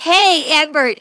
synthetic-wakewords
ovos-tts-plugin-deepponies_Cozy Glow_en.wav